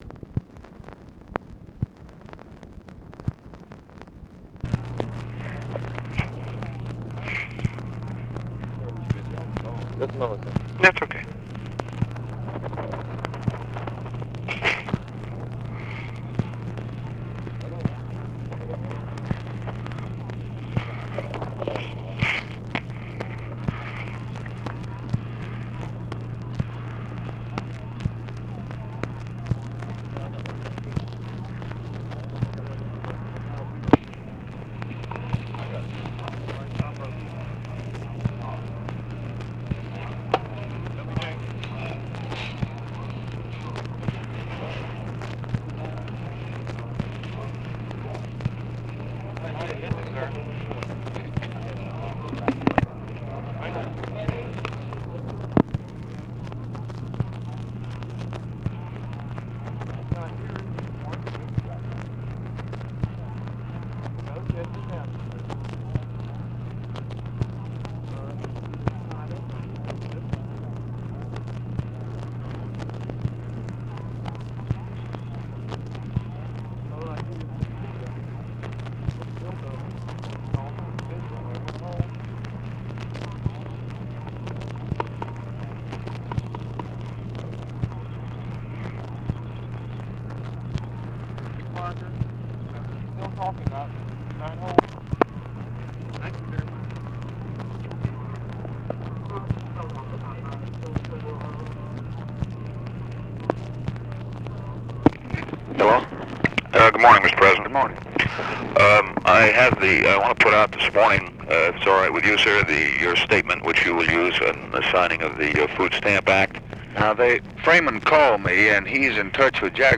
Conversation with MAC KILDUFF and OFFICE CONVERSATION, August 31, 1964
Secret White House Tapes